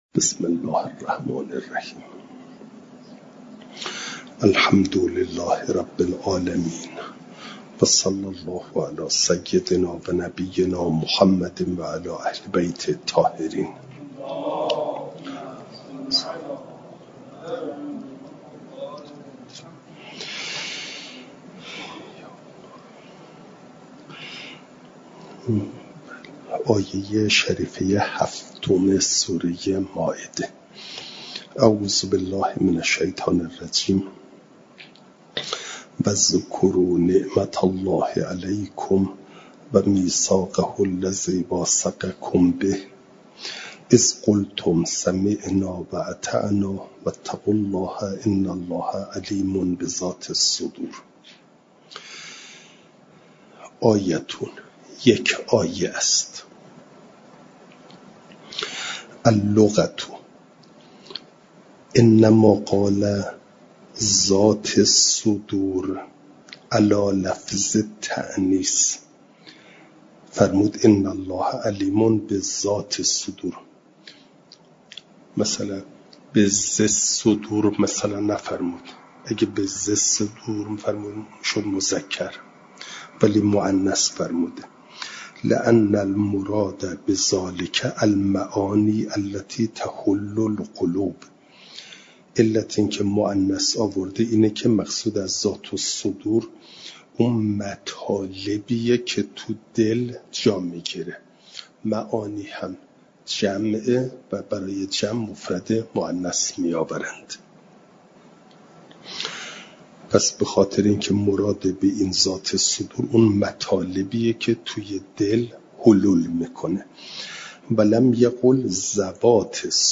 جلسه چهارصد و بیست و یک درس تفسیر مجمع البیان